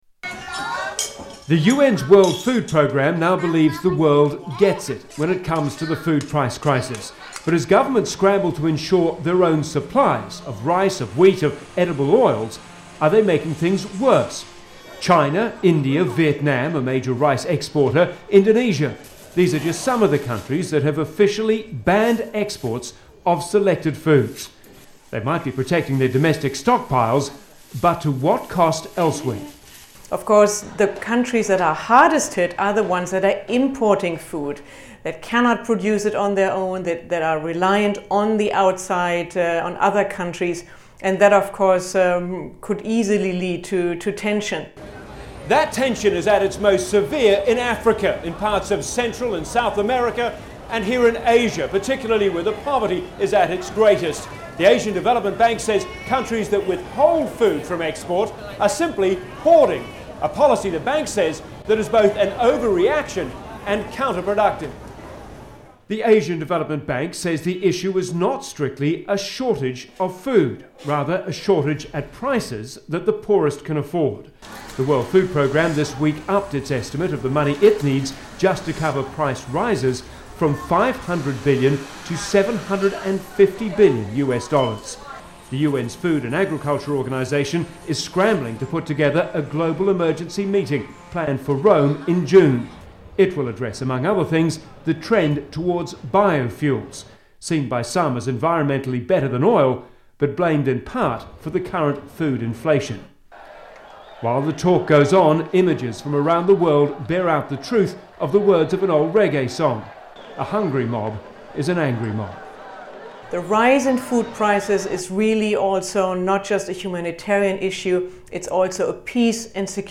Tags: Historical Media News Top News Top News Stories